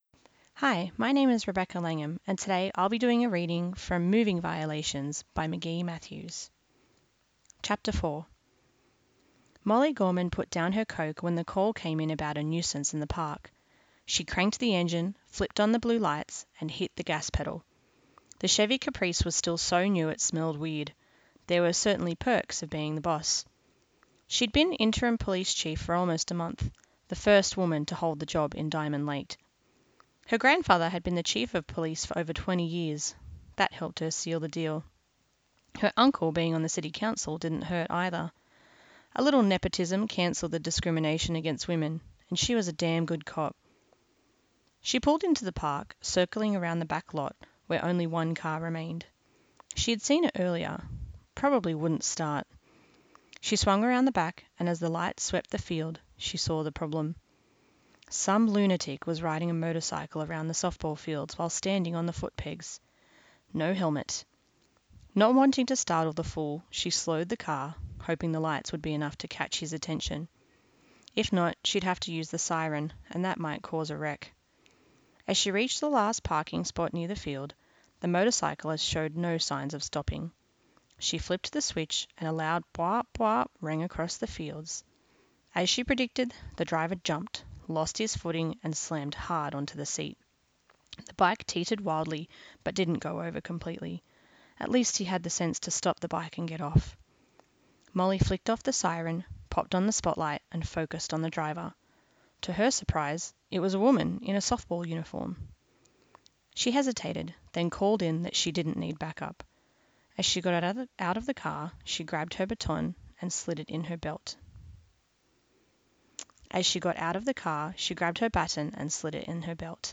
Reading from Moving Violations